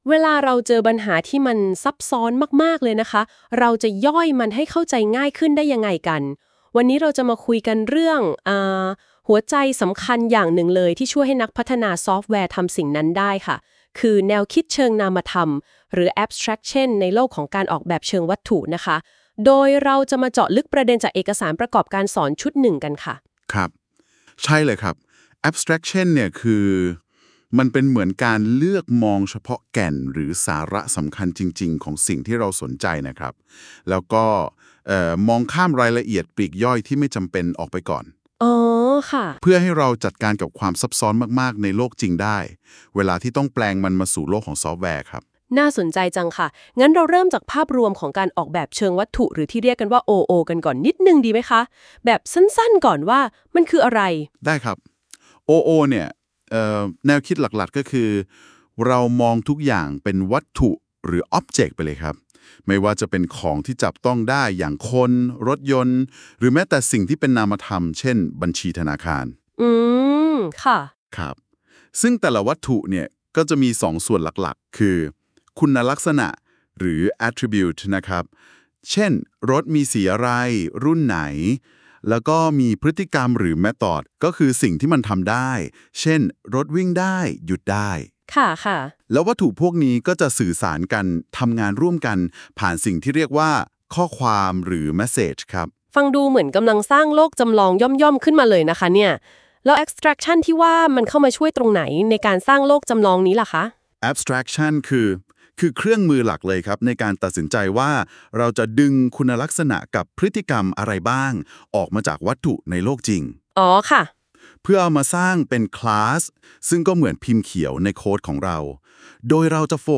Lec02_Object-Oriented Abstraction_ Concepts_LM.wav